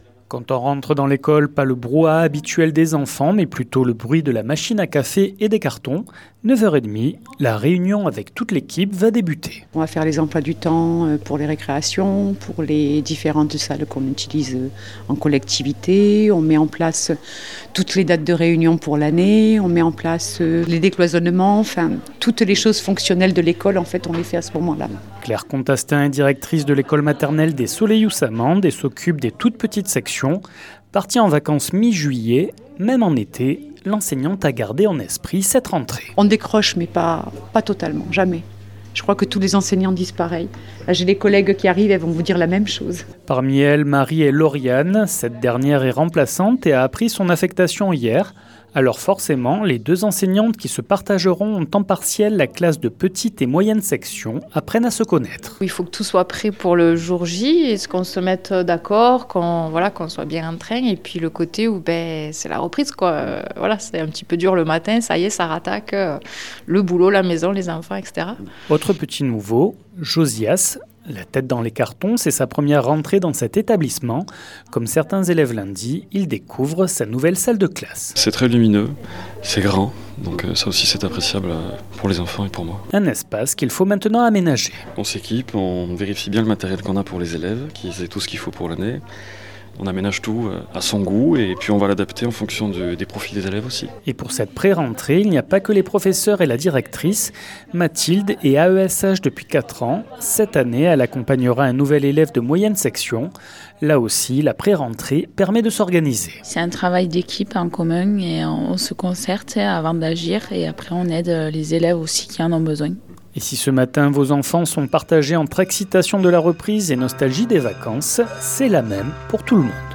Et si nos chérubins retournent à l’école aujourd’hui, leurs professeurs, eux, ont déjà repris le travail la semaine dernière. Vendredi dernière, 48FM s’est rendu à Mende, à l’école maternelle des Solelhons pour la prérentrée.
Reportage